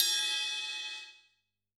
CYCdh_Kurz01-Ride02.wav